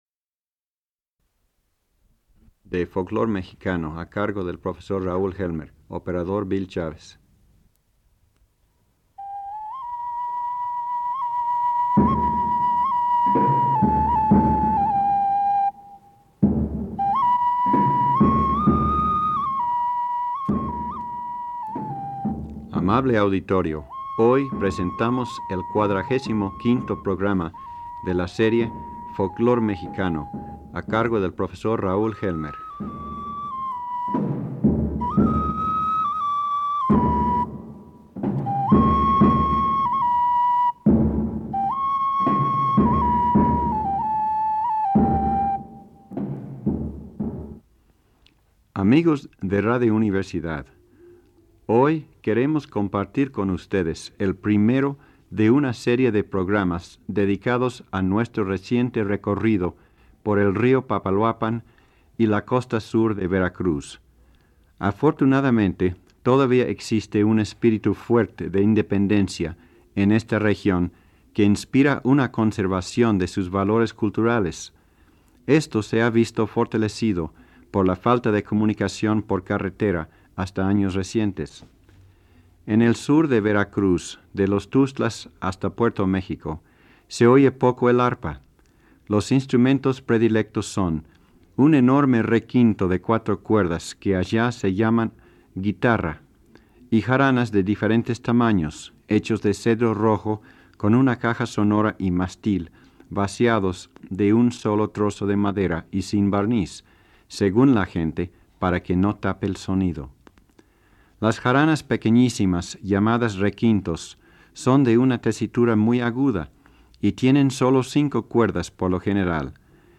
Folclor mexicano fue una serie de programas radiofónicos de Radio UNAM